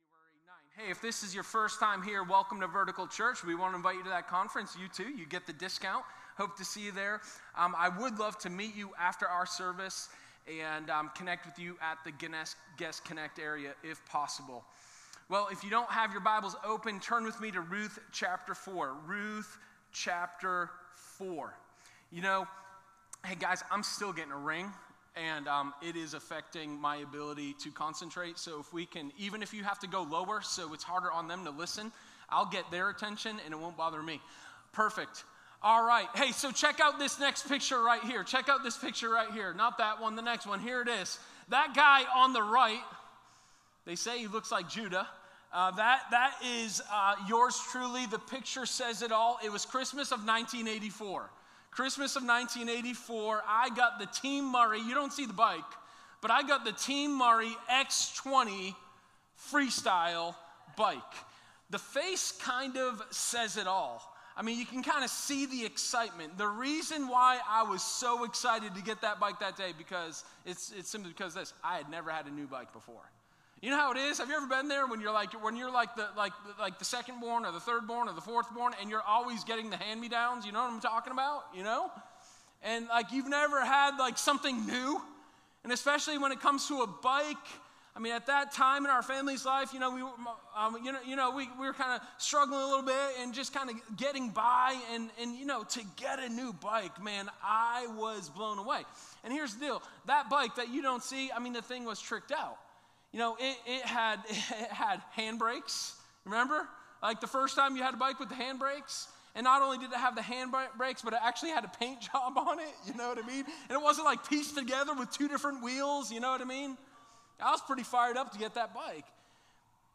Sermon1213_A-Joyful-Redemption.m4a